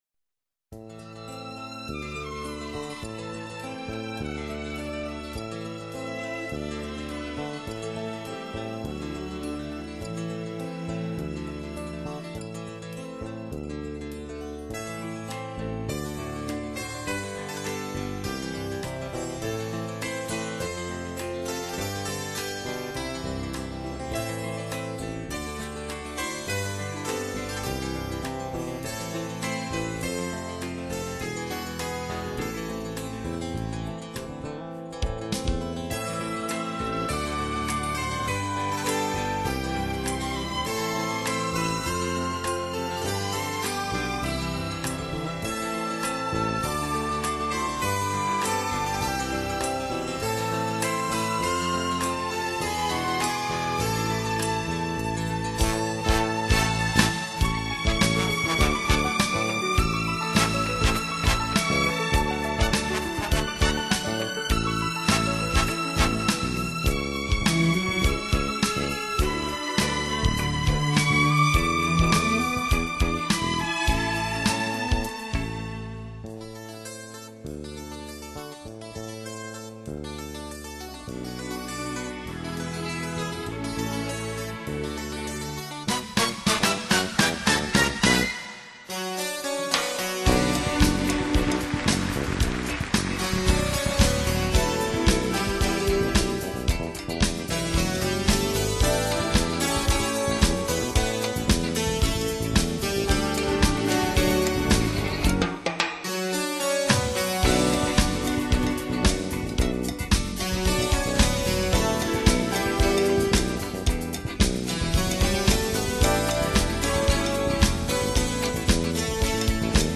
“情调音乐的使者”